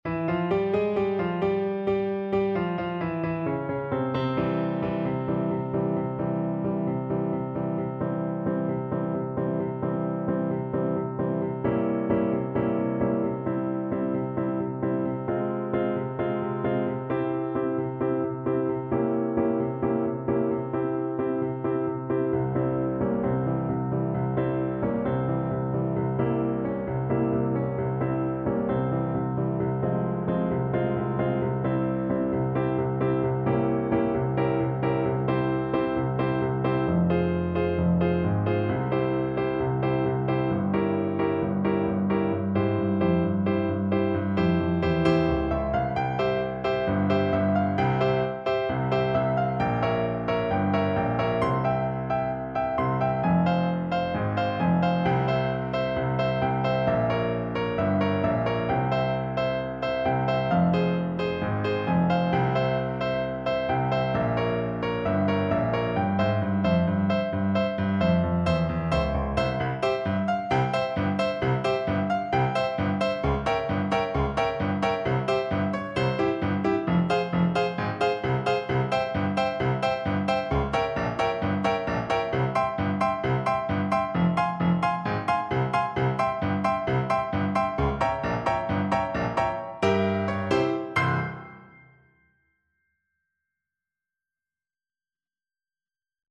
Molto allegro =c.132
4/4 (View more 4/4 Music)
C5-Db6
Traditional (View more Traditional Voice Music)
world (View more world Voice Music)